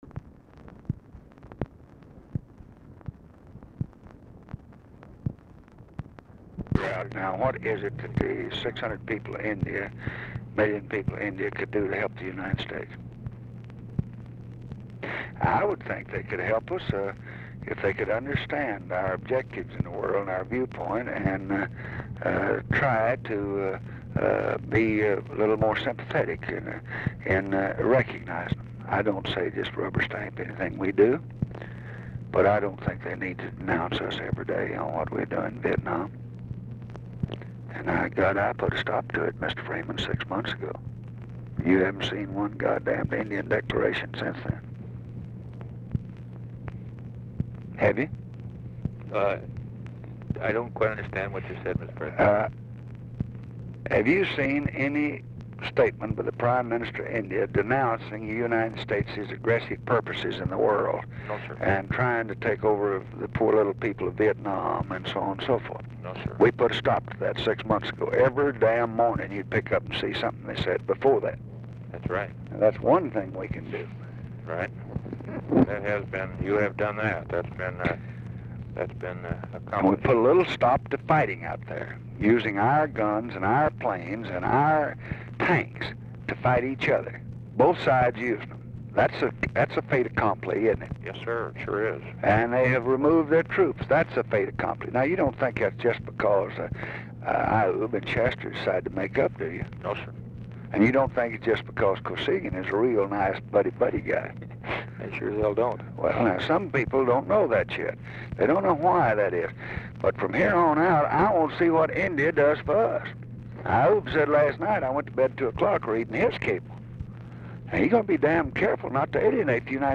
Telephone conversation
CONTINUES FROM PREVIOUS RECORDING; RECORDING ENDS BEFORE CONVERSATION IS OVER
Format Dictation belt
Location Of Speaker 1 Mansion, White House, Washington, DC